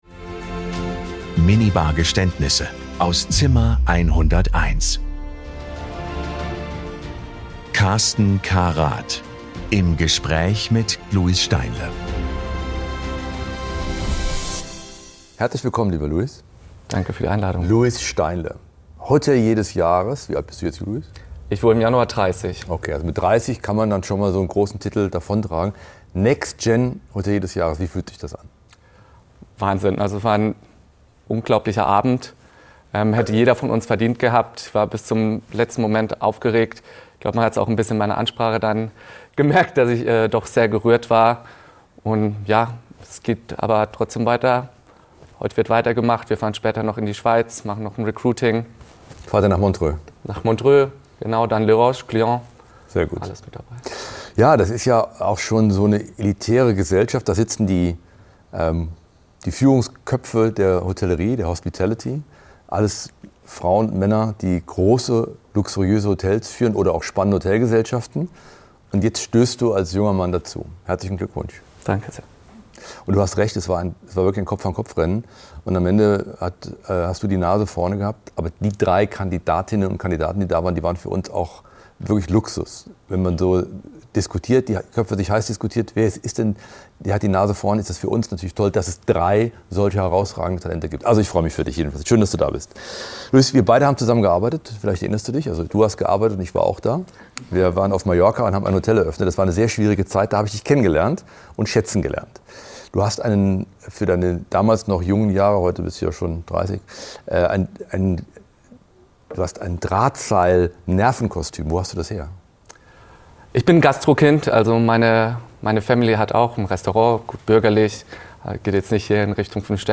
Es wird gelacht, reflektiert und manchmal auch gestanden. Hier wird Hospitality persönlich.